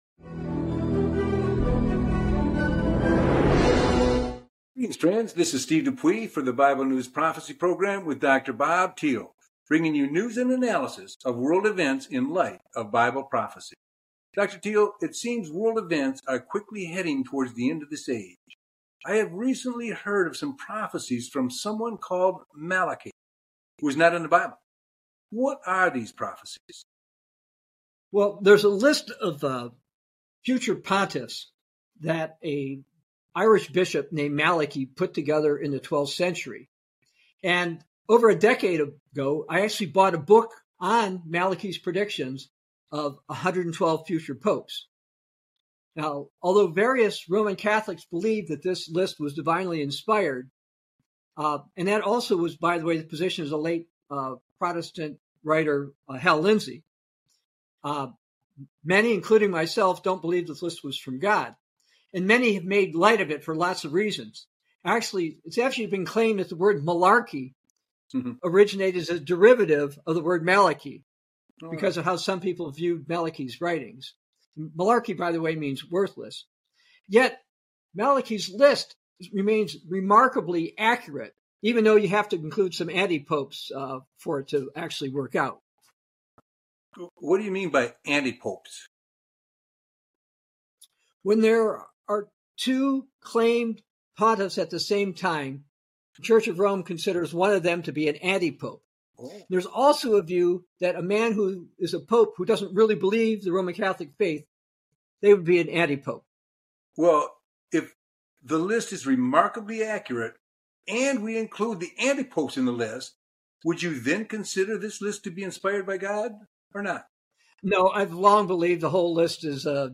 Bible News Prophecy Talk Show